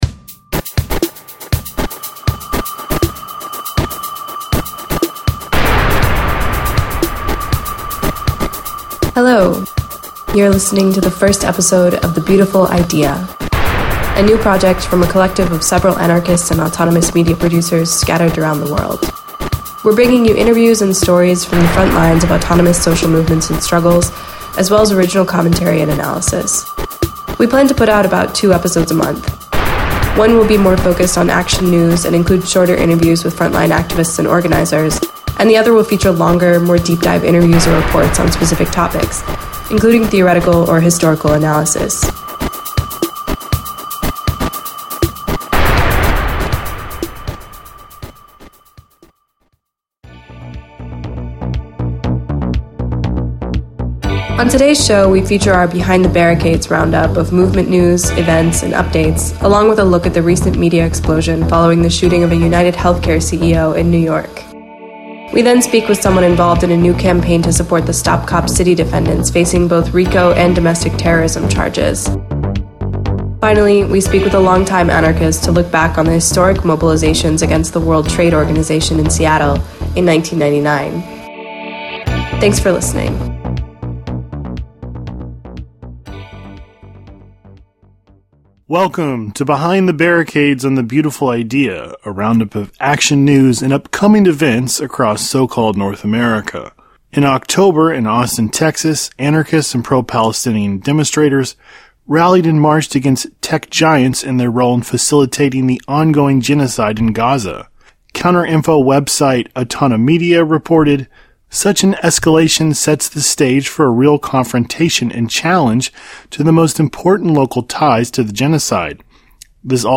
The first show will be focused on news roundups, interviews from the frontline, and discussions about current events.